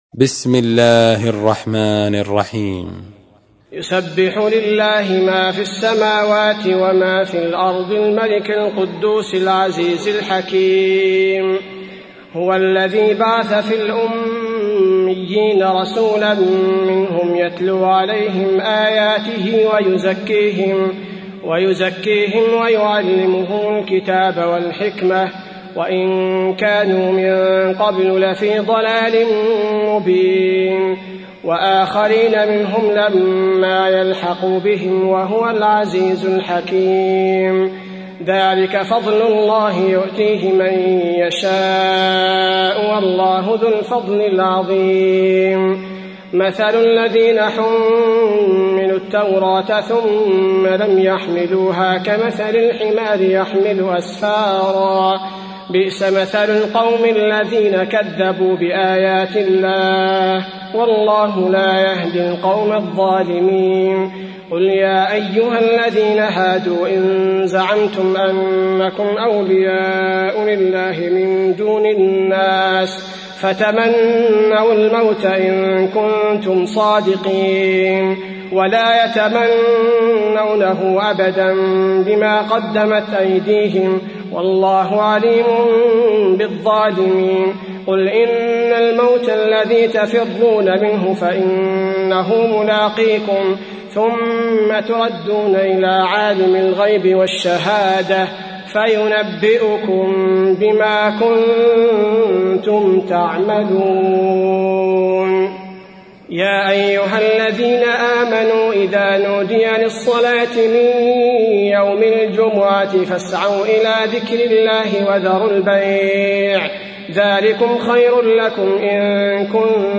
تحميل : 62. سورة الجمعة / القارئ عبد البارئ الثبيتي / القرآن الكريم / موقع يا حسين